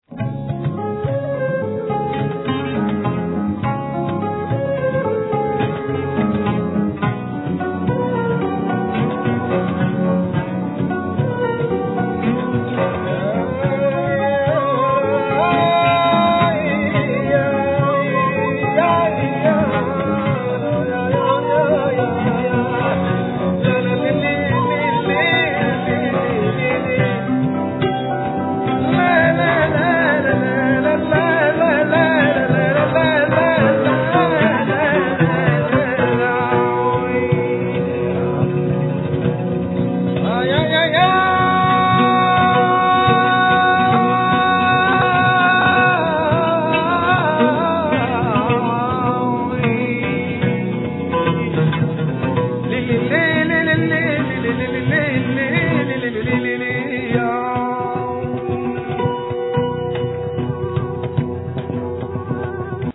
Cimbaloom, Vocals, Drums
Tenor saxophone
Percussions
Flute
Trumpet
Trombone
Bass
Tablas
Alt saxophone